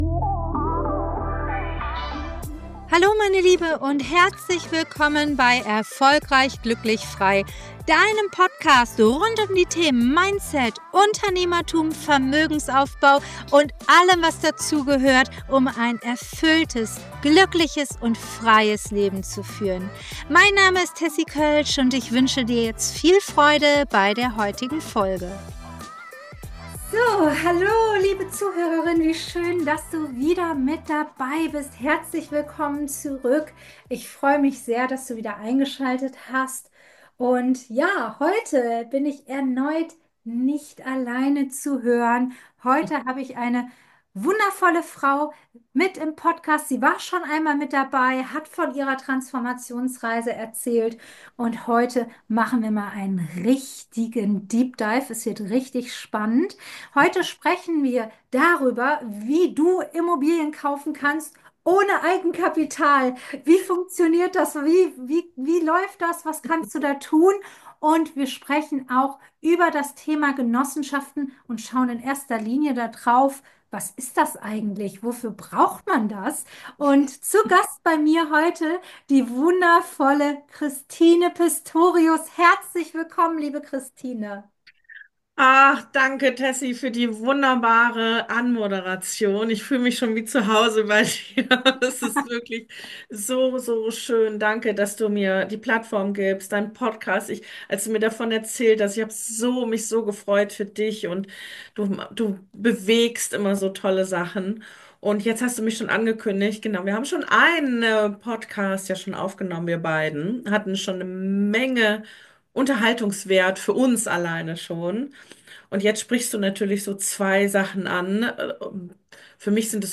#3 Fix & Flip ohne Eigenkapital und smarte Strukturen mit Genossenschaften – Interview